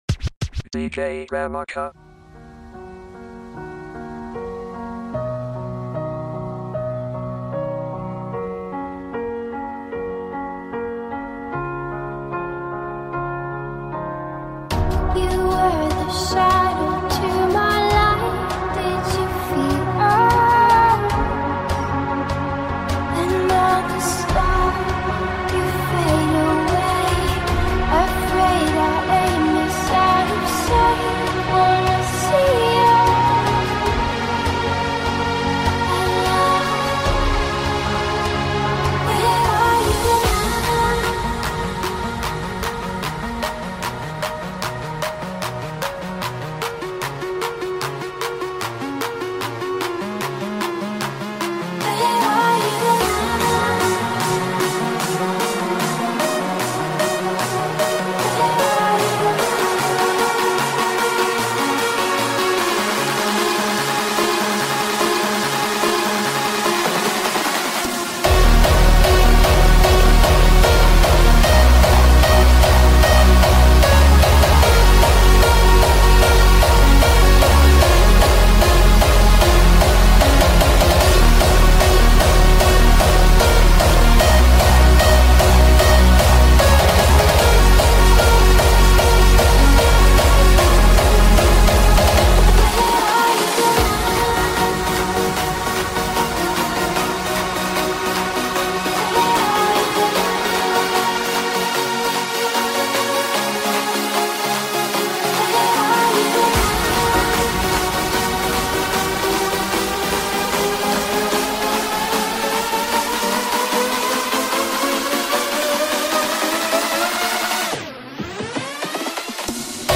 Hardstyle Remix